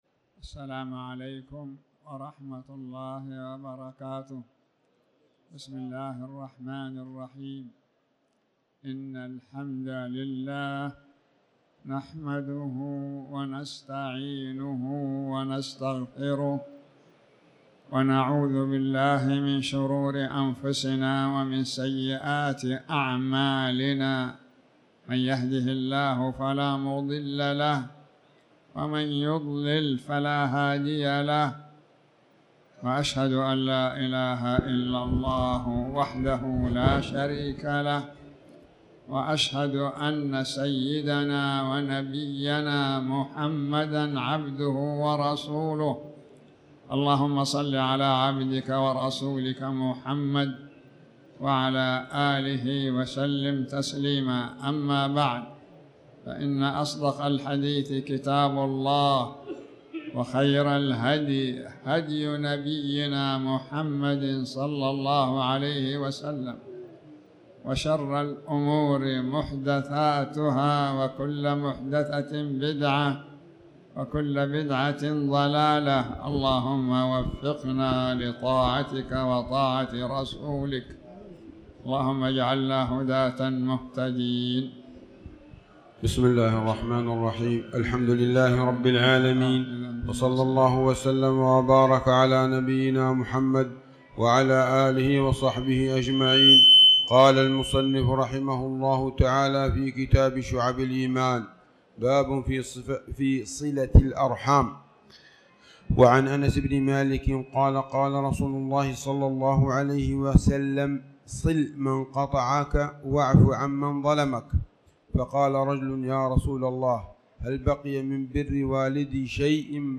تاريخ النشر ٢١ جمادى الأولى ١٤٤٠ هـ المكان: المسجد الحرام الشيخ